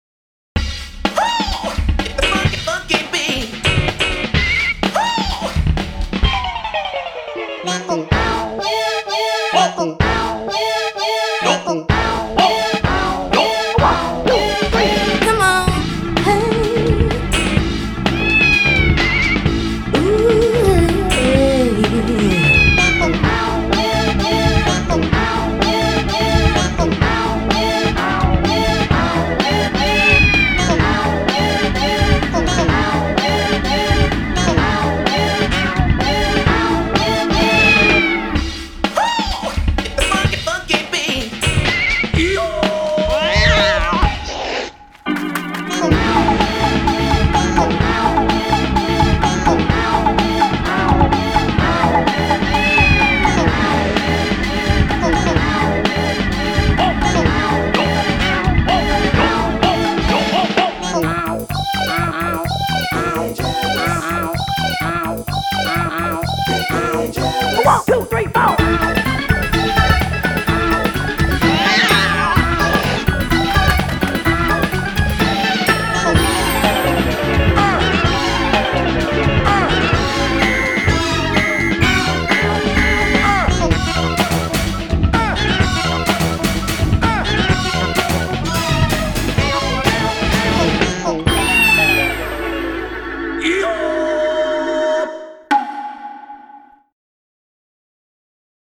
BPM127
Audio QualityPerfect (High Quality)
Genre: FUNK. This song is a remix of the traditional song